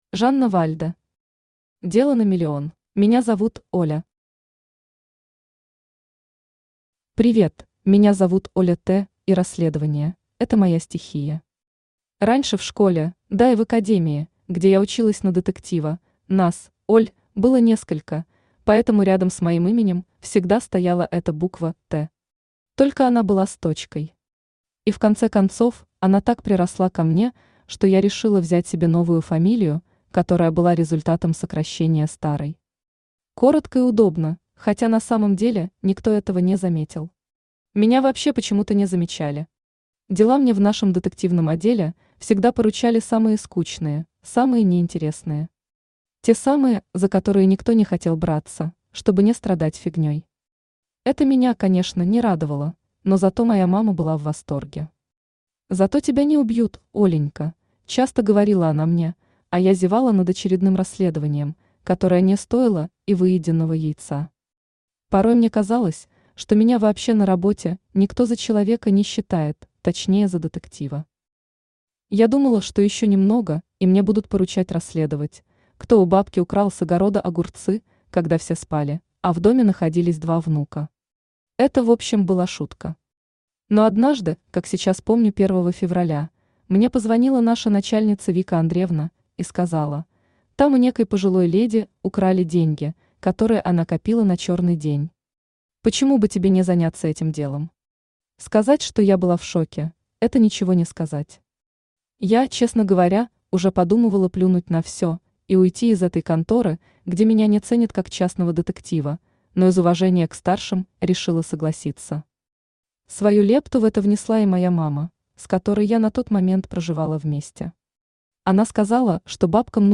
Аудиокнига Дело на миллион | Библиотека аудиокниг
Aудиокнига Дело на миллион Автор Жанна Вальда Читает аудиокнигу Авточтец ЛитРес.